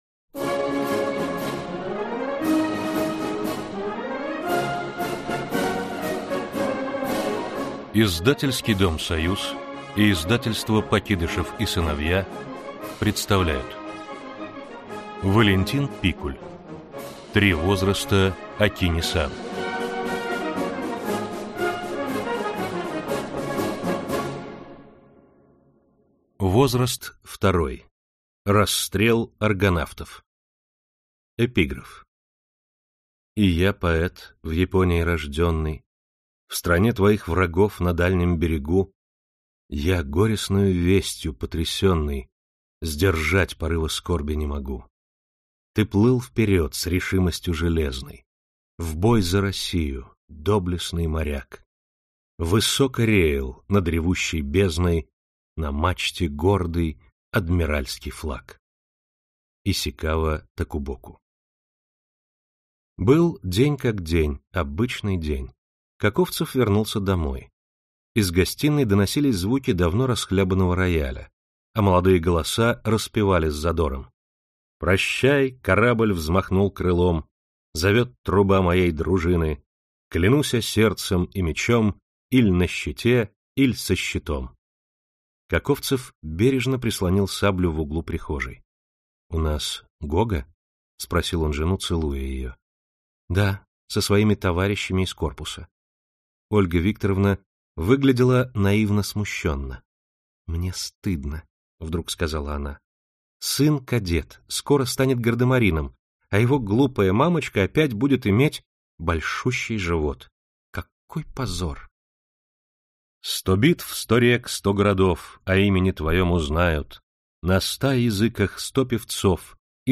Аудиокнига «Три возраста Окини-сан» Возраст второй. Расстрел аргонавтов | Библиотека аудиокниг